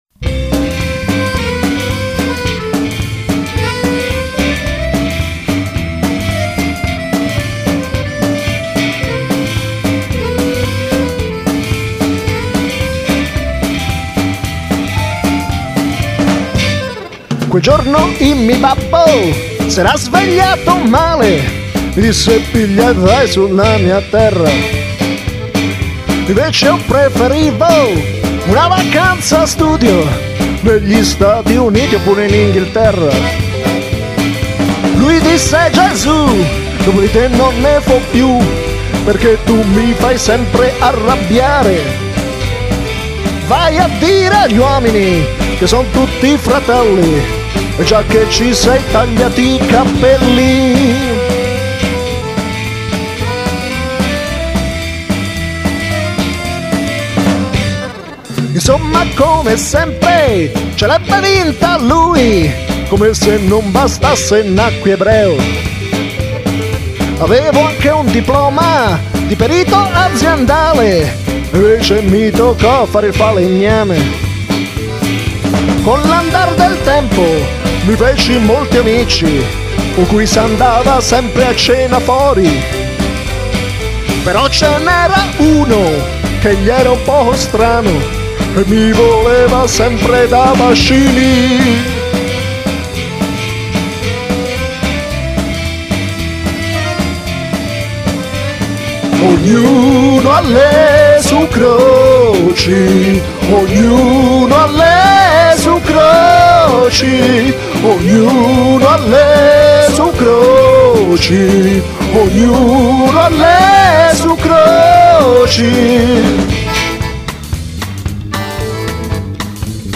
Un inno (quasi) religioso, scritto nell'ormai lontano 1992